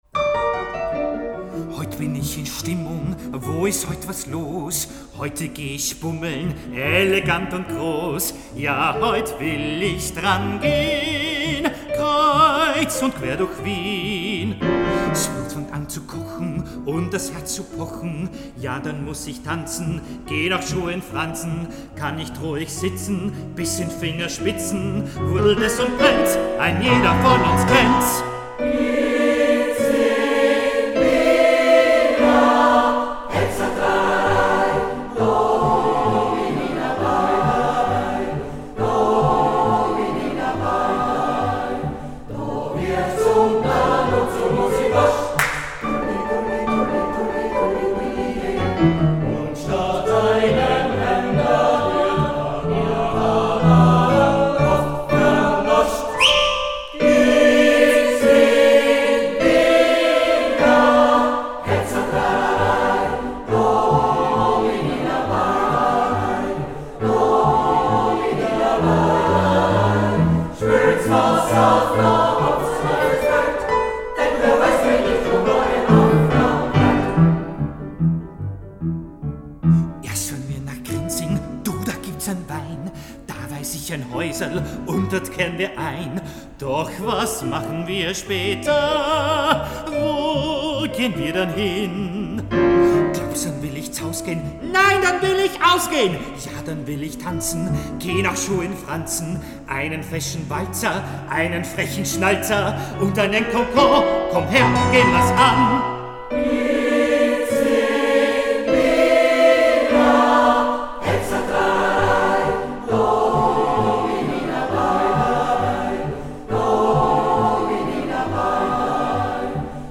Studioaufnahme, Mai 2023